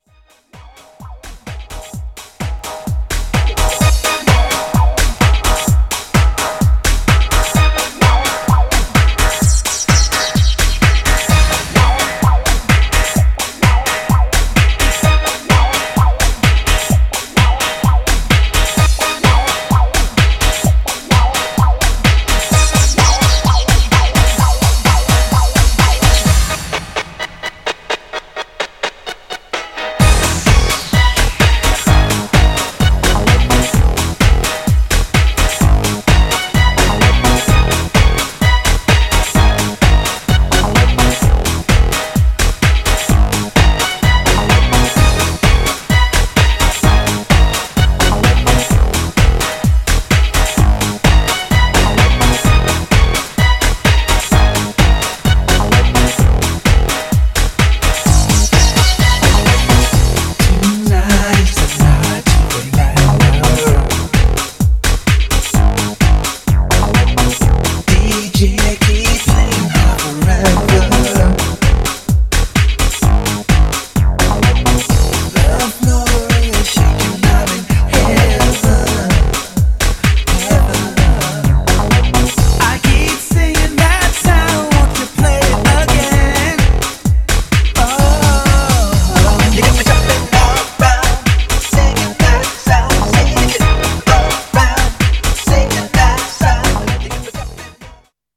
Styl: Disco, House